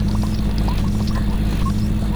airplaneSqueaker2.wav